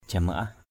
/ʥa-ma-ah/ (đg.) quy y (chỉ thầy Acar mới tập sự) = = novice (désigne les prêtres Bani qui viennent d’entrer en religion). acar jamaah acR jmaH thầy Acar tập sự (đẳng...